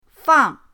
fang4.mp3